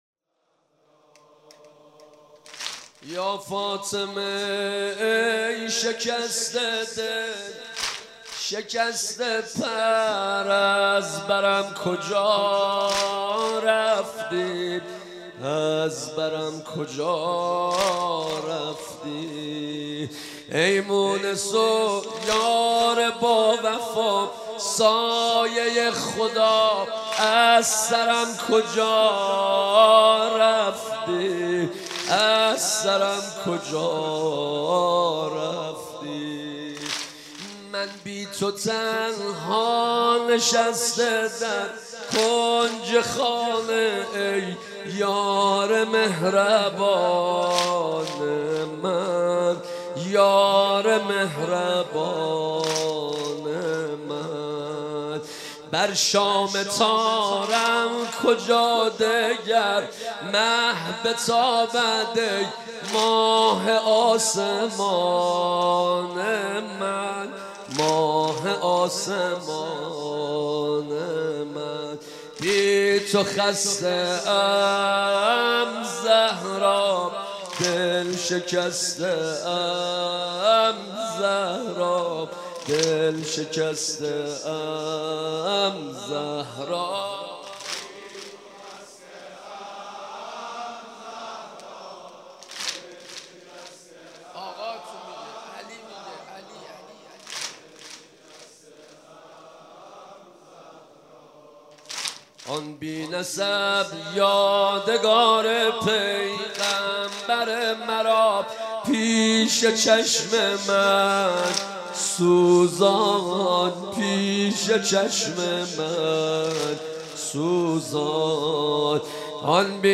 مداحی و نوحه
[واحد] [شب دوم فاطمیه اول]